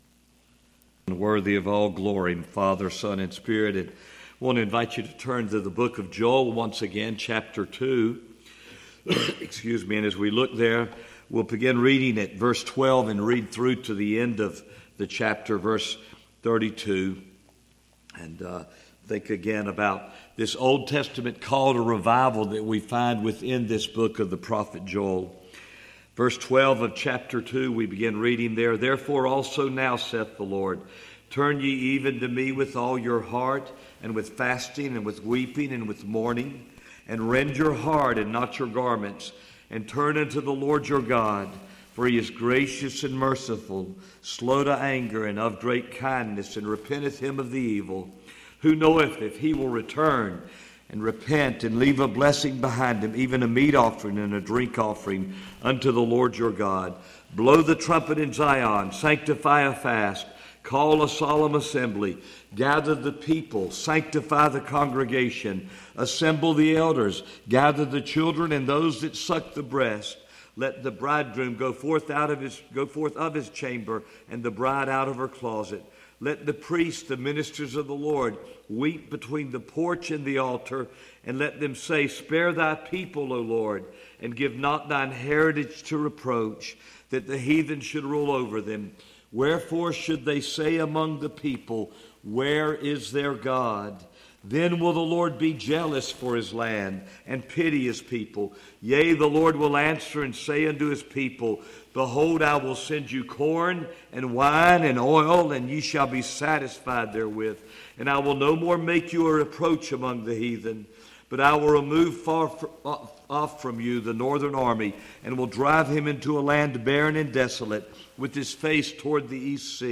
Session: Morning Session